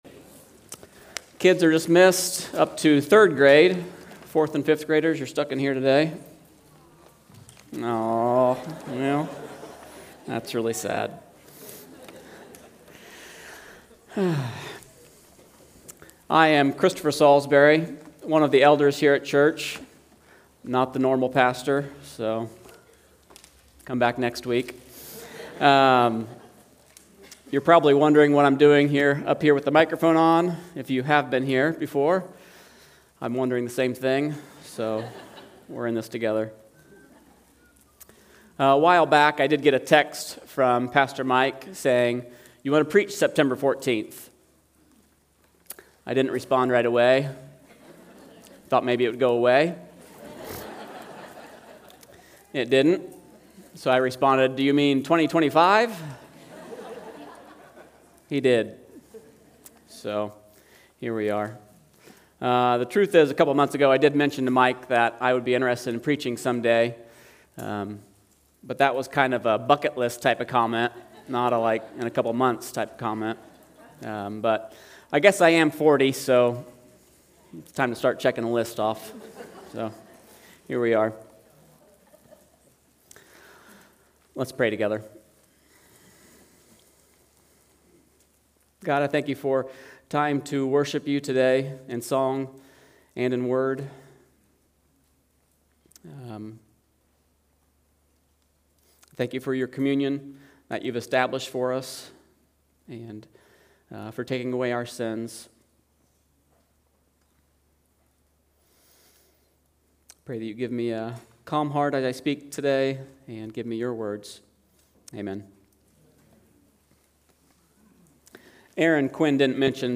9-14-25-Sunday-Service.mp3